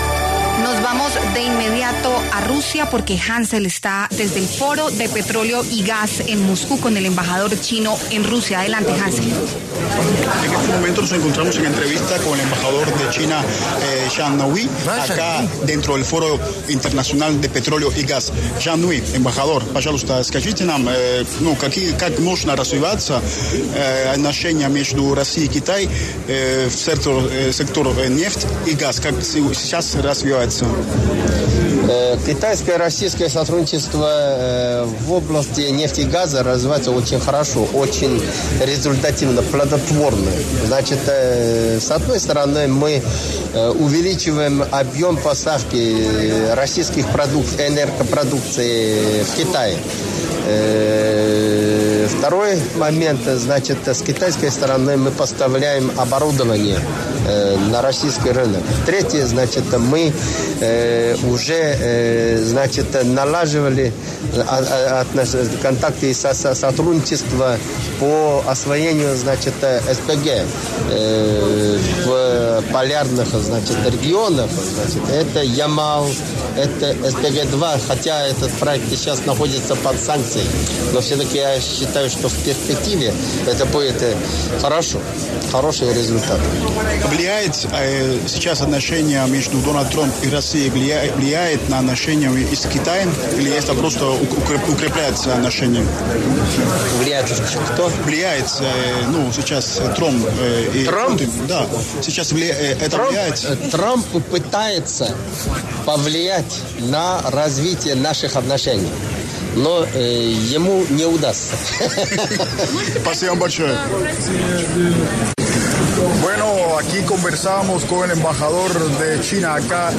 Zhang Hanhui, embajador de China ante Rusia, conversó con La W desde el Foro de Petróleo y Gas que se lleva a cabo en Moscú.